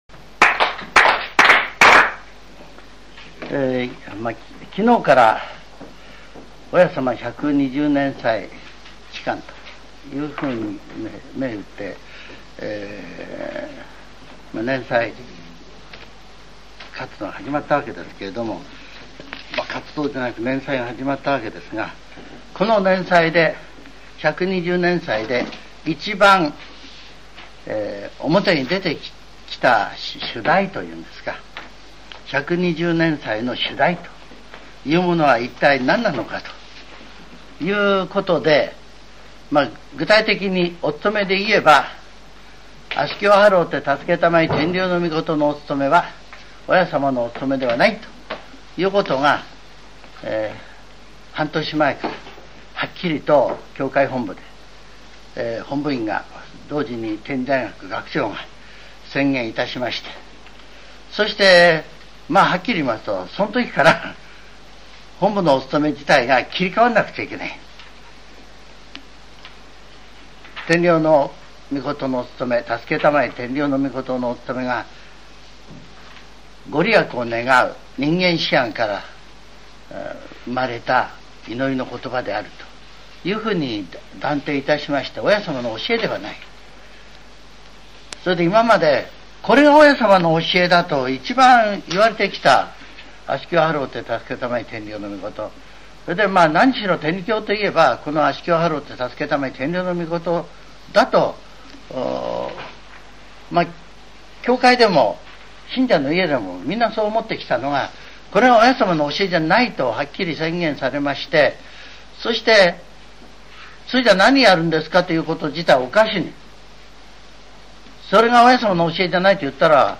全70曲中48曲目 ジャンル: Speech